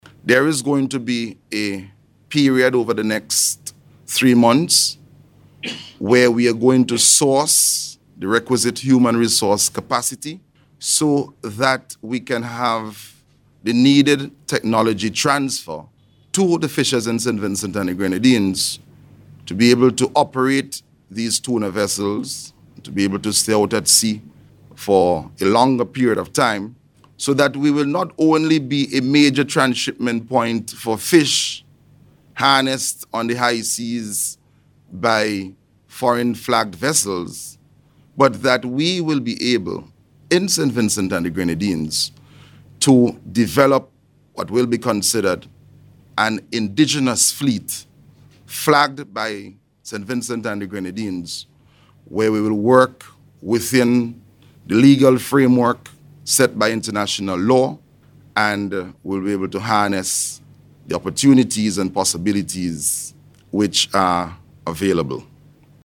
Speaking at a Signing Ceremony at the Fisheries Conference Room this week, Minister Caesar said steps are also being taken to enhance the capacity of local Fisherfolk.